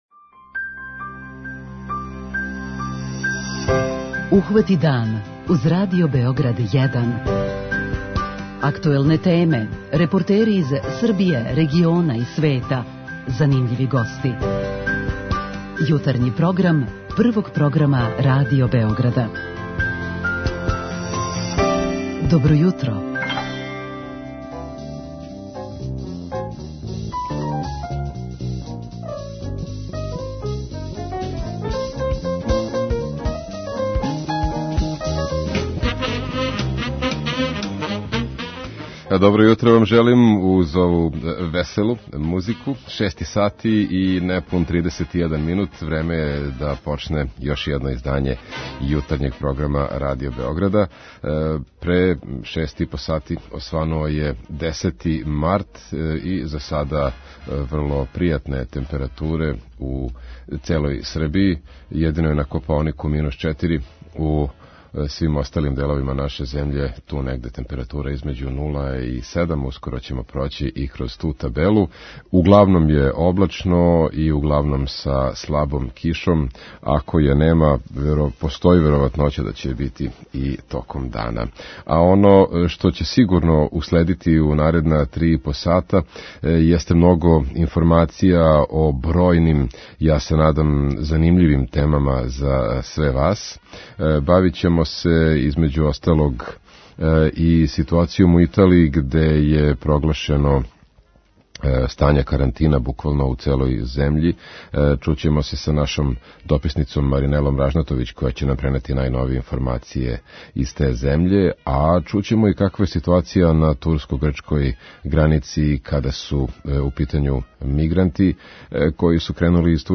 Осим ситуације са коринавирусом, пратимо и шта се догађа са мигрантима на турско-грчкој граници. У програм укључујемо дописнице РТС из Рима и Атине које ће нам пренети најновије информације.